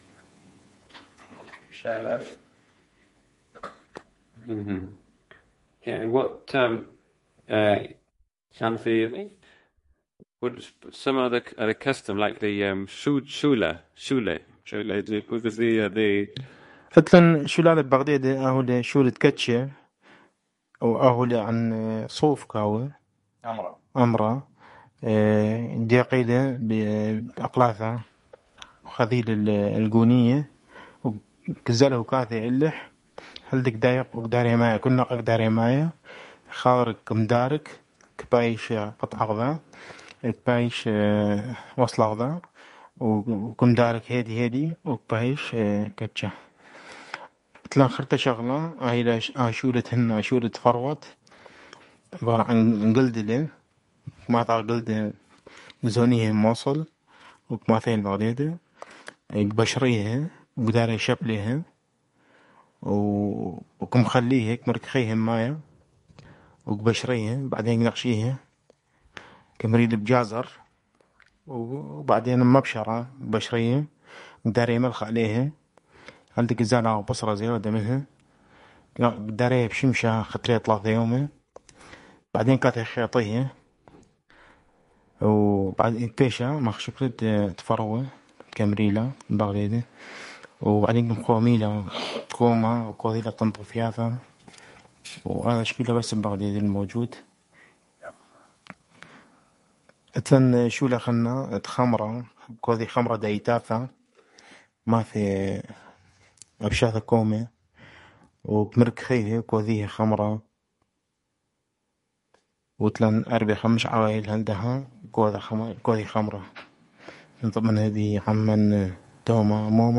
Qaraqosh (Baghdede): Professions